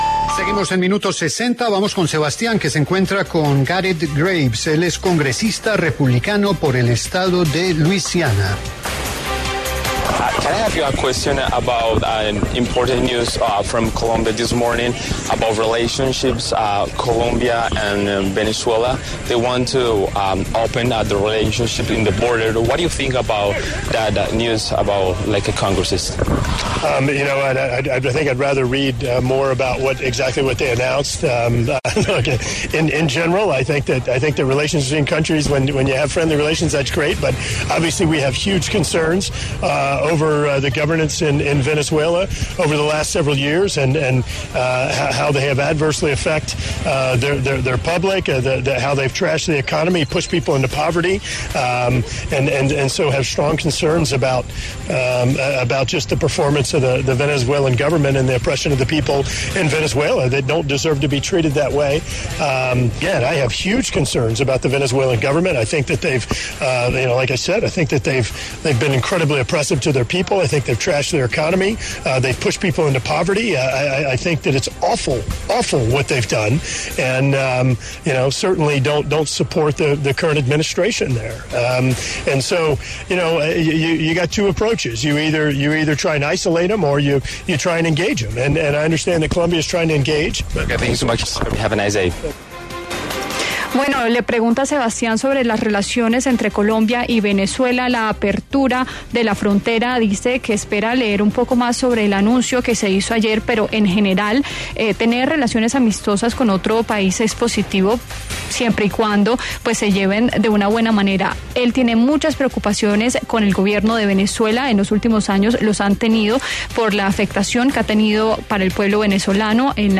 A propósito del anuncio del restablecimiento de las relaciones entre Colombia y Venezuela a partir del 7 de agosto, La W conversó con Garret Graves, congresista estadounidense del Partido Republicano por Luisiana, sobre las opiniones desde Estados Unidos.
En el encabezado escuche las declaraciones de Garret Graves, congresista estadounidense del Partido Republicano por Luisiana.